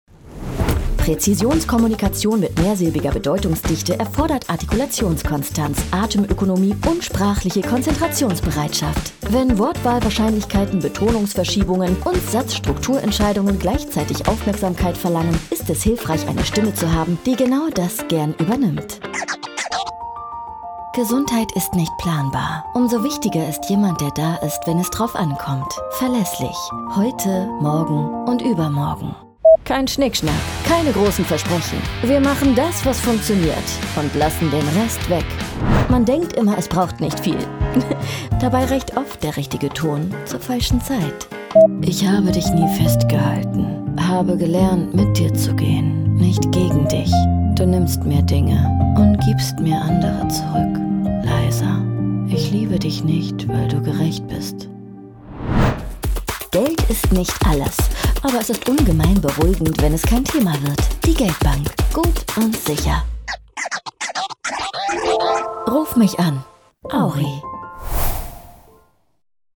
Synchron VOX